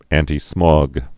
(ăntē-smŏg, -smôg, ăntī-)